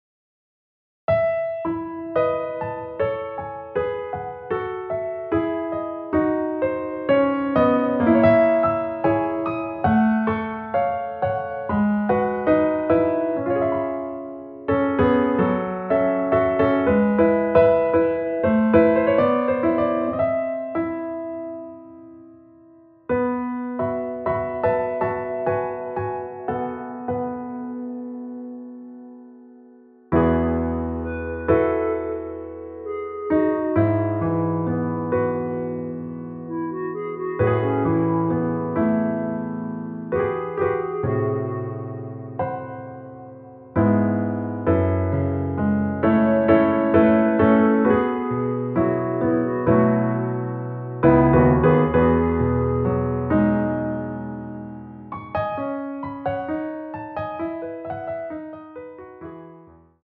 원키에서(-1)내린 멜로디 포함된 MR입니다.(미리듣기 확인)
앞부분30초, 뒷부분30초씩 편집해서 올려 드리고 있습니다.